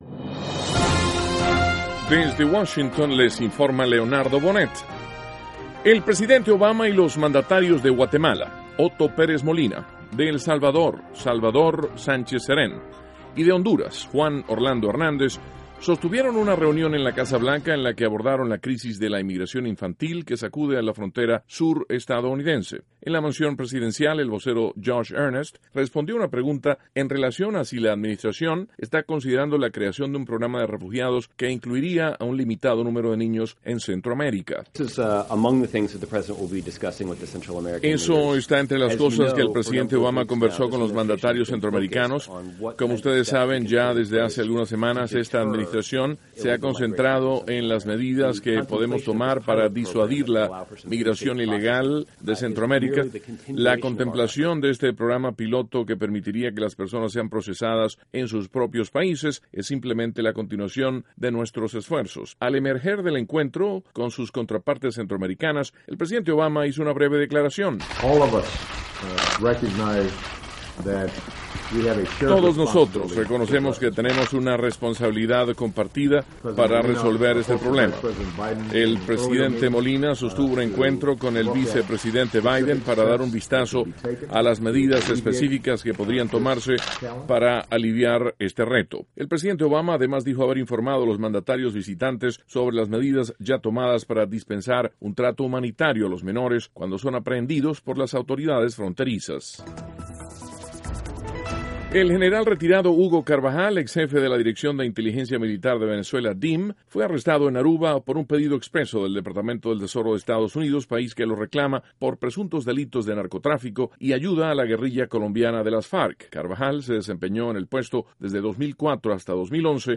NOTICIAS - VIERNES, 25 DE JULIO, 2014
Duración: 3:26 Contenido: 1.- Mandatarios centroamericanos se reúnen en la Casa Blanca con el Presidente Obama. (Sonido Obama y Earnest) 2.- Abogados del general venezolano, Hugo Carvajal, consideran que el funcionario no deber permanecer arrestado en Aruba.